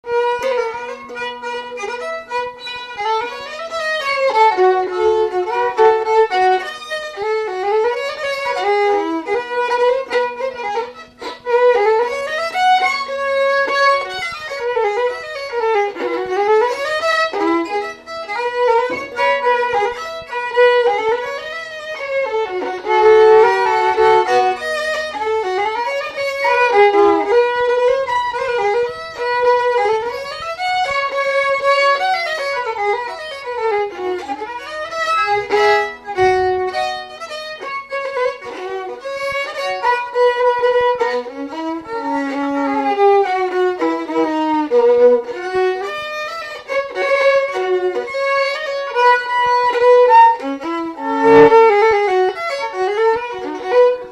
Polka
Résumé Instrumental
danse : polka
Pièce musicale inédite